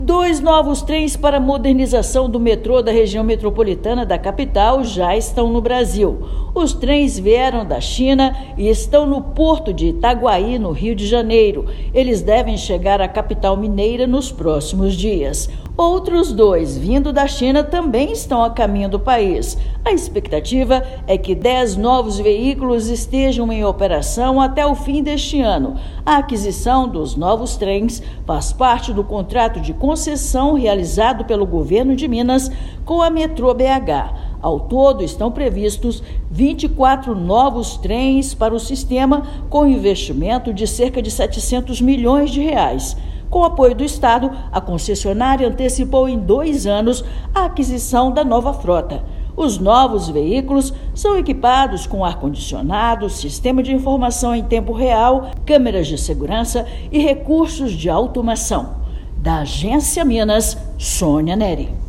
Veículos integram o pacote de investimentos e ampliam o conforto e a eficiência do transporte. Ouça matéria de rádio.